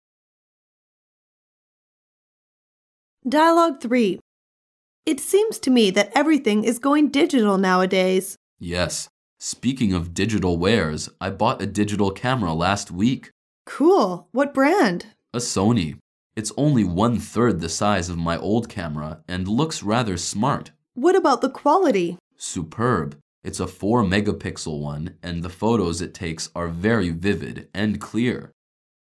Dialoug 3